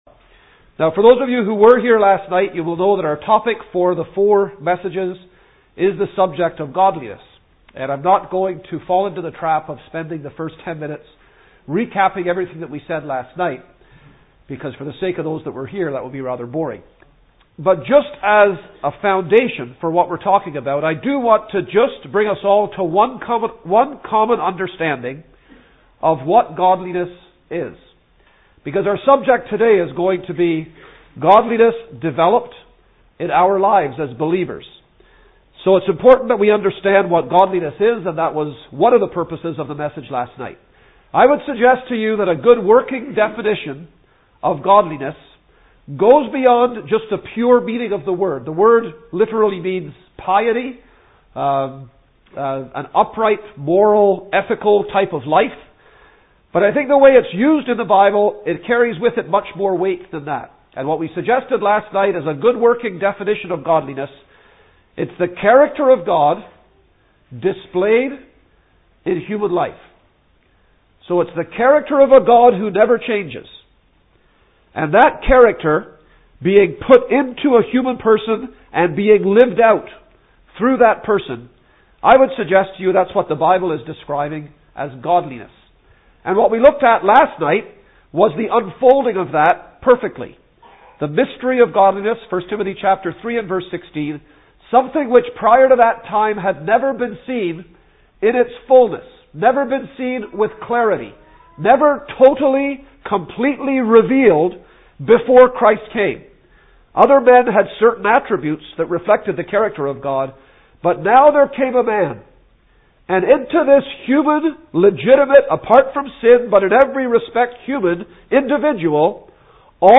While believers must flee from the manifestations of the flesh, they must actively pursue godliness (1 Tim 6:11). Christians are encouraged to see that godliness is not only of value in the life which is to come, but also in “the life that now is” (1 Tim 4:8) (Message preached 14th Mar 2015)